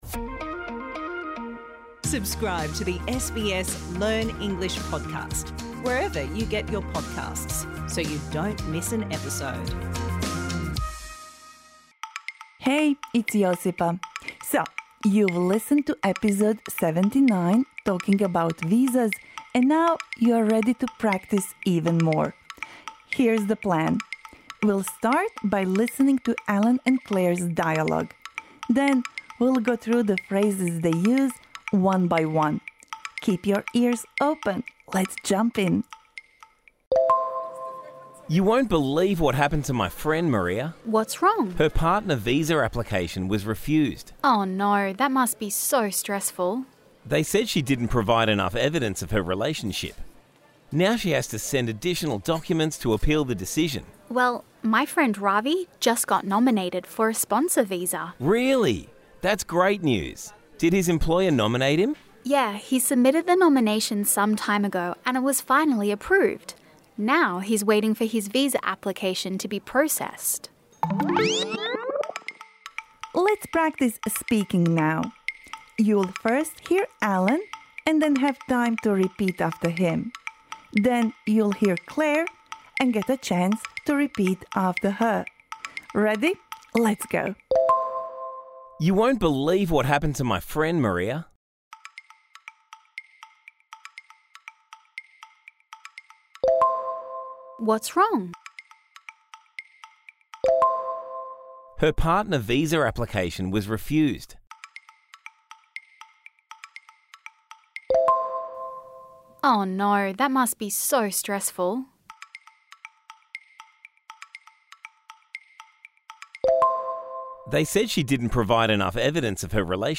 Allan You won’t believe what happened to my friend Maria.